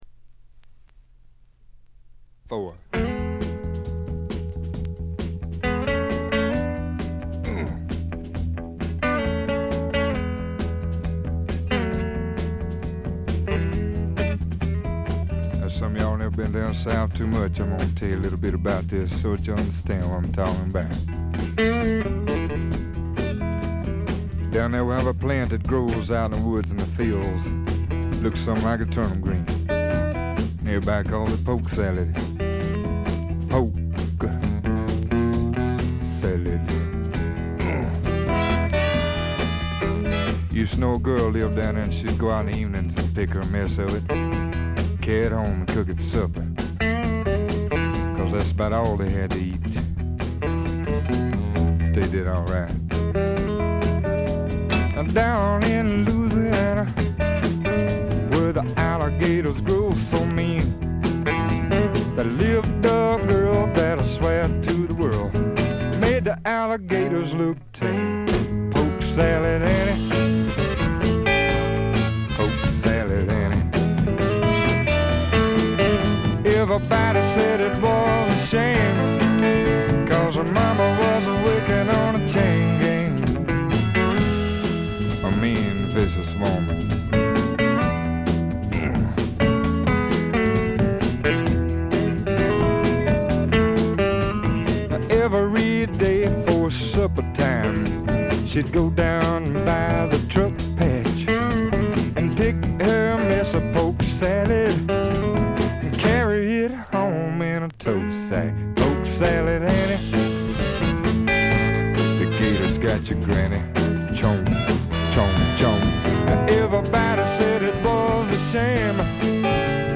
見た目よりよくなくて、バックグラウンドノイズ、チリプチ多め大きめ。
試聴曲は現品からの取り込み音源です。